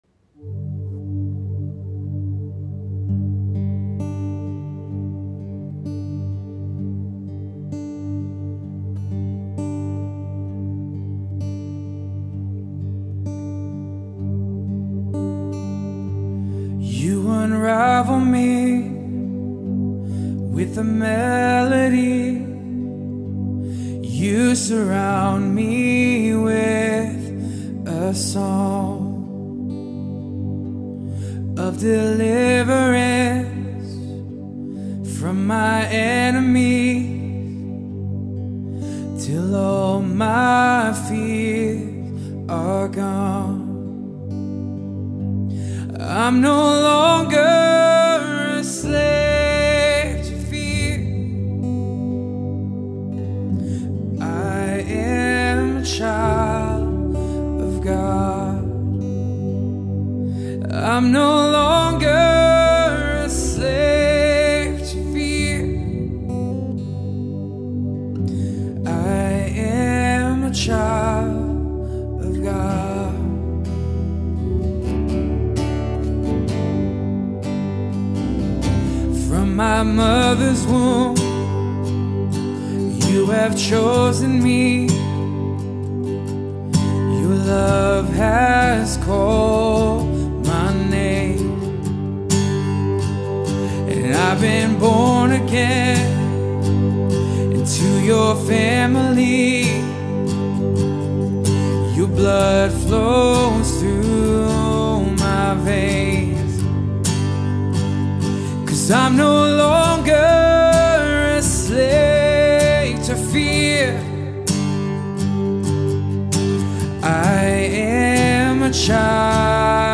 3. Sunday Worship – Second Song: